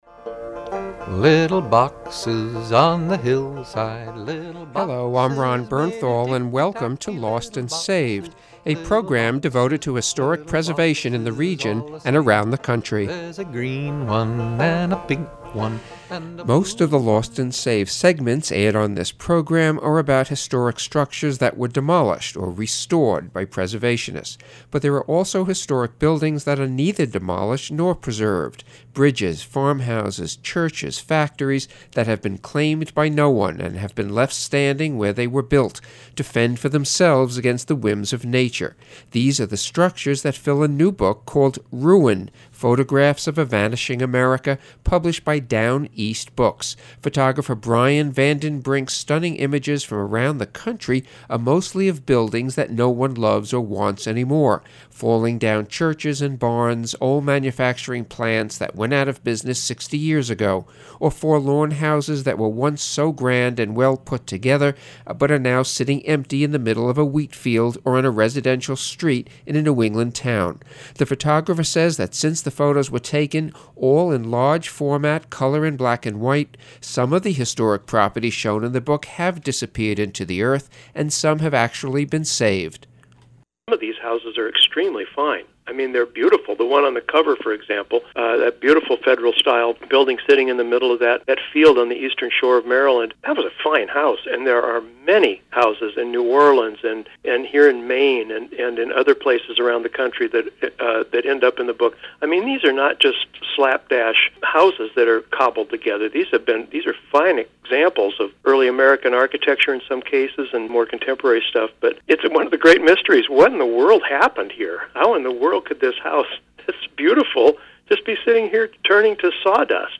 NATIONAL PUBLIC RADIO INTERVIEW